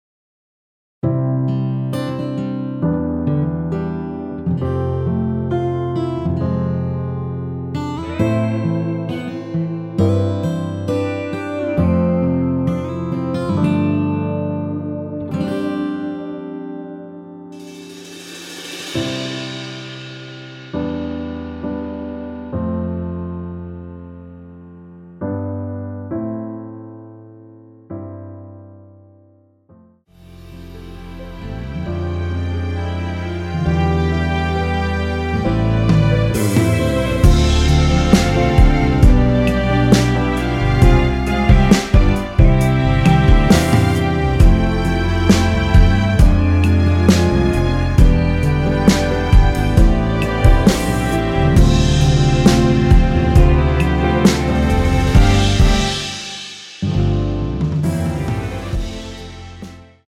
원키에서(-3)내린 MR입니다.
앞부분30초, 뒷부분30초씩 편집해서 올려 드리고 있습니다.